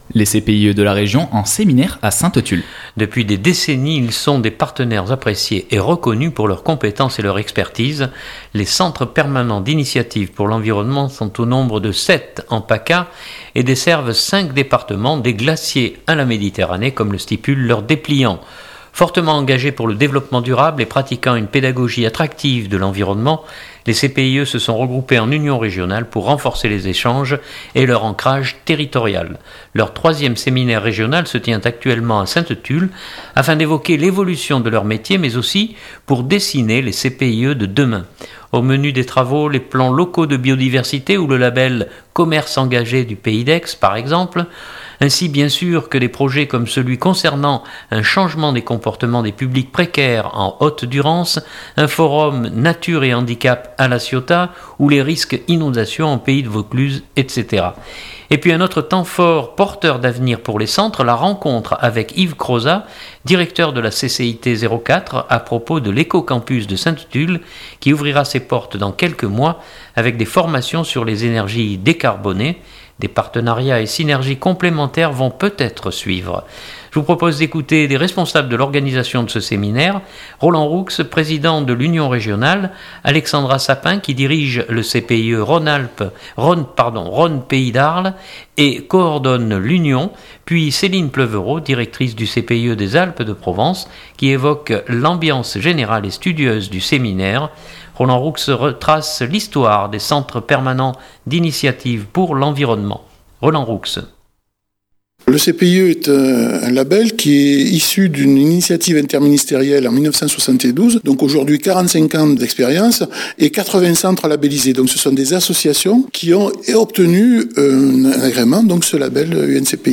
Je vous propose d’écouter des responsables de l’organisation de ce séminaire.